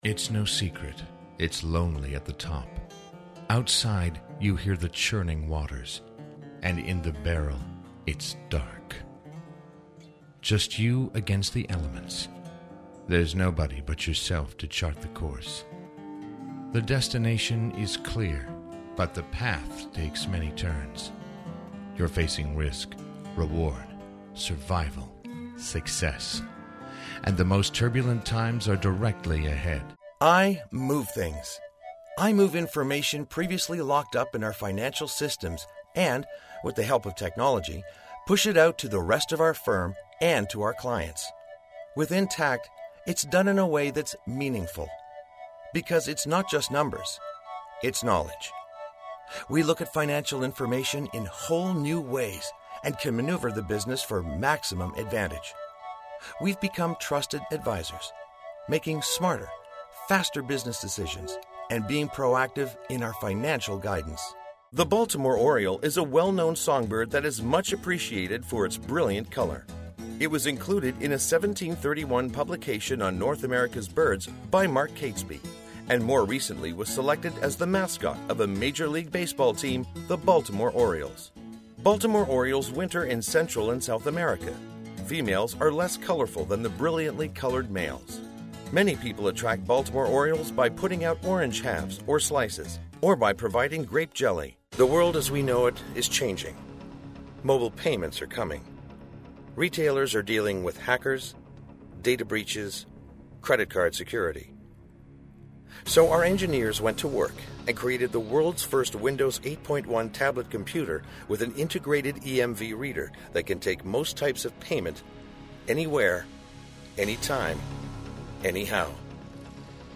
NARRATION VOICE DEMO